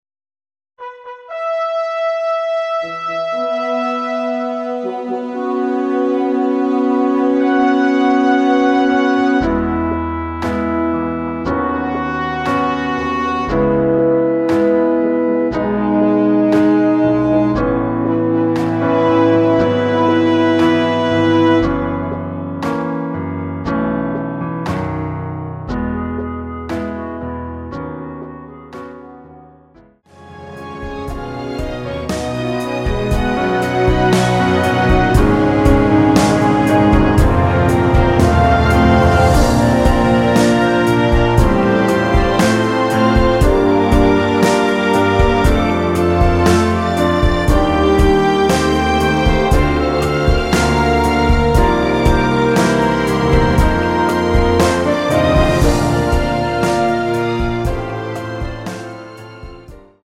엔딩이 너무 길어 라이브에 사용하시기 좋게 짧게 편곡 하였습니다.(원키 미리듣기 참조)
원키에서(-1)내린 멜로디 포함된 MR입니다.
앞부분30초, 뒷부분30초씩 편집해서 올려 드리고 있습니다.
중간에 음이 끈어지고 다시 나오는 이유는